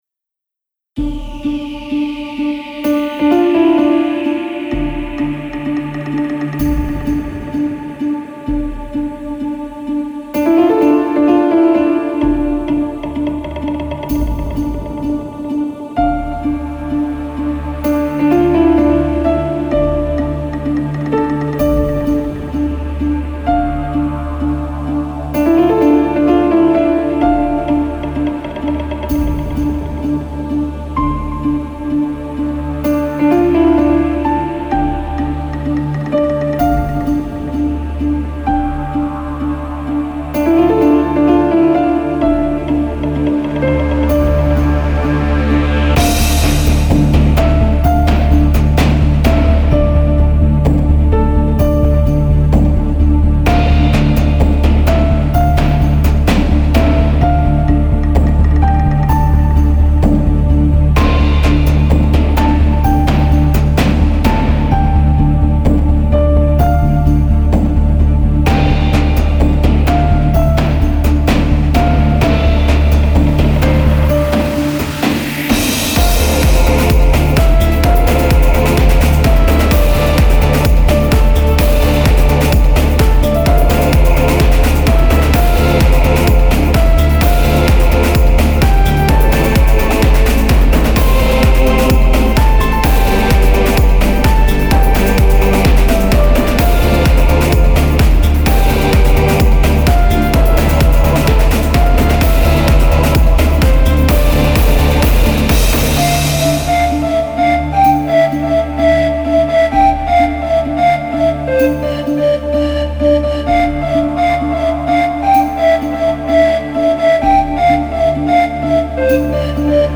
Genre : Musiques de films/jeux